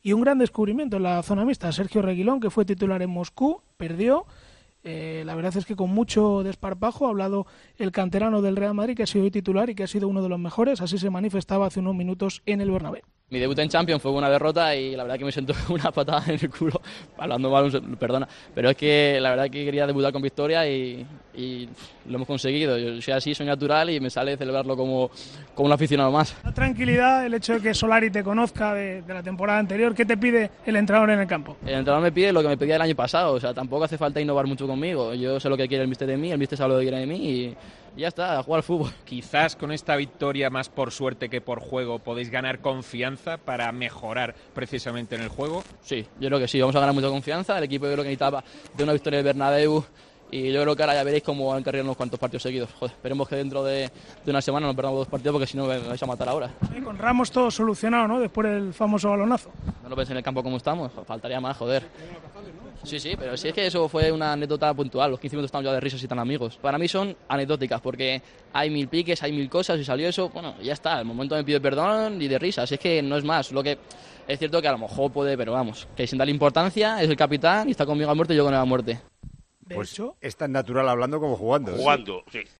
Sergio Reguilón, en zona mixta: "El equipo necesitaba una victoria en el Bernabéu, veréis cómo ahora viene una racha positiva".